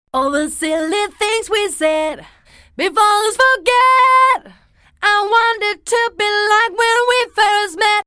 sinon une façon très simple de réglage est un réglage en Chromatique; l'effet est alors un peu plus doux
109vox-tunechrom.mp3